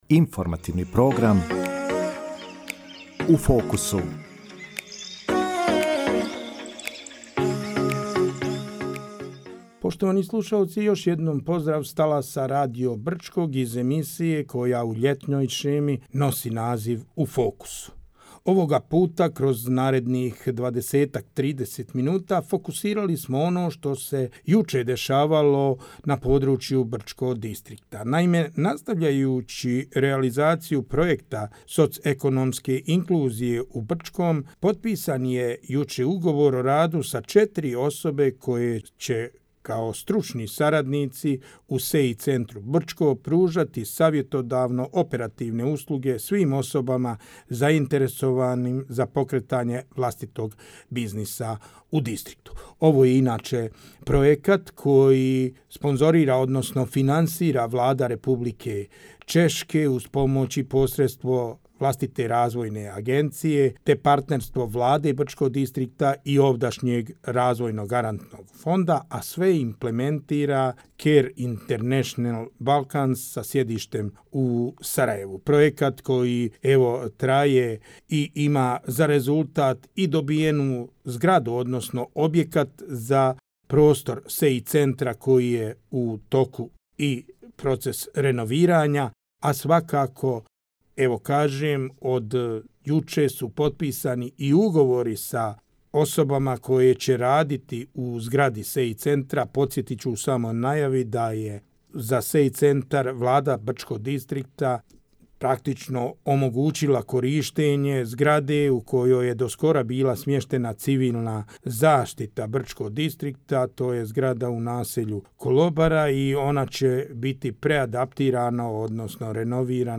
U emisiji smo razgovarali sa zaposlenicima SEI Centra Brčko o planovima rada ovog centra u narednom periodu.